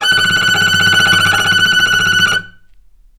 healing-soundscapes/Sound Banks/HSS_OP_Pack/Strings/cello/tremolo/vc_trm-F#6-mf.aif at bf8b0d83acd083cad68aa8590bc4568aa0baec05
vc_trm-F#6-mf.aif